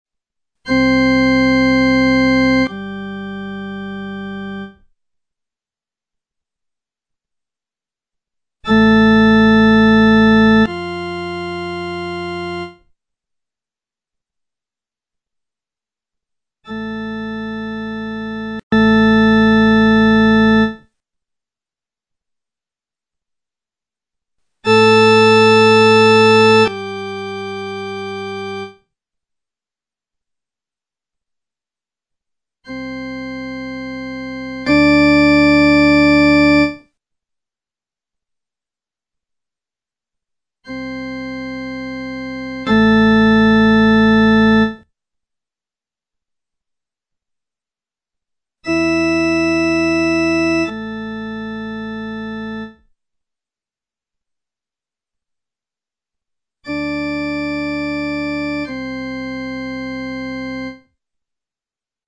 Ascolto selettivo
Ascolta queste 8 coppie di suoni. Per ognuna di essa indica se il primo suono è forte (F) o piano (P) rispetto al secondo
intensita_03.mp3